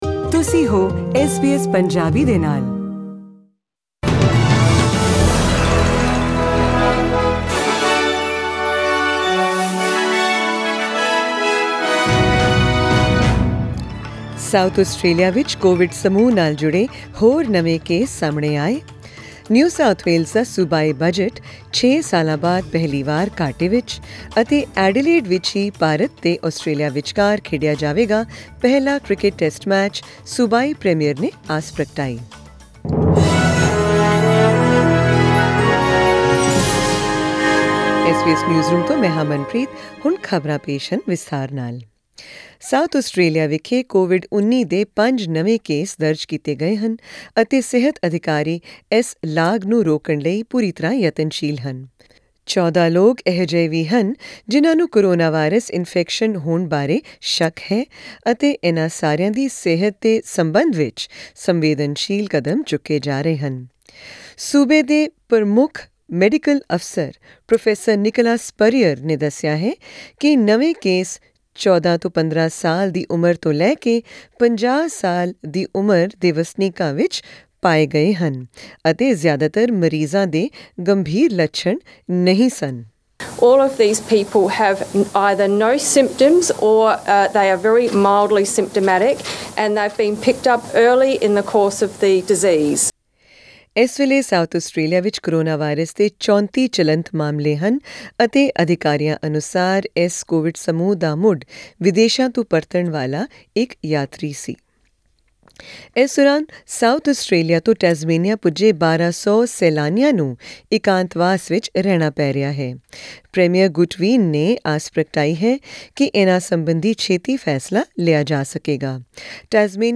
Tonight’s SBS Punjabi news bulletin brings you COVID updates from around Australia, as well as India and China; hear about the NSW state budget and the $100 voucher being given for dining and entertainment; and South Australia’s Premier hopeful that the first test match between Australia and India can still go ahead at the Adelaide Oval.